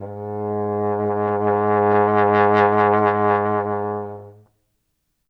trombone
Gs2.wav